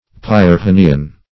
Search Result for " pyrrhonean" : The Collaborative International Dictionary of English v.0.48: Pyrrhonean \Pyr*rho"ne*an\, Pyrrhonic \Pyr*rhon"ic\, a. [L. Pyrrhon[^e]us: cf. F. pyrrhonien.]